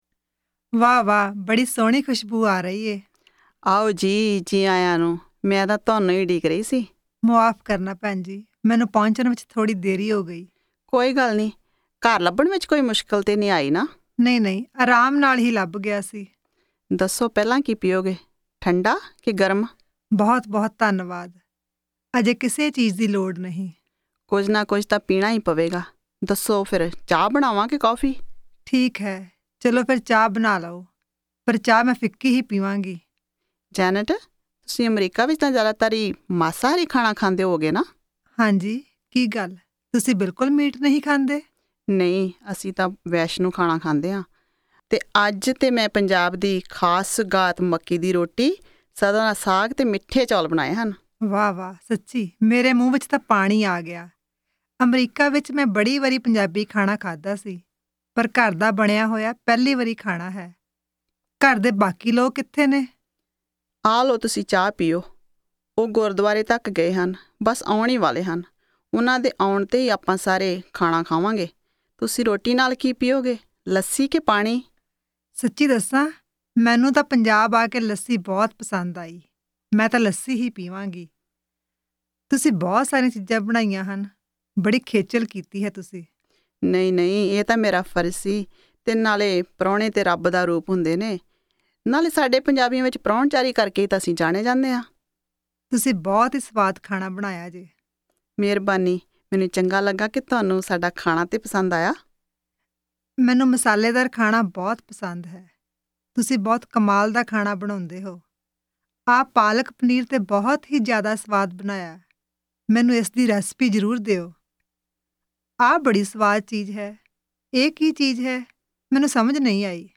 Punjabi Conversation 14 Listen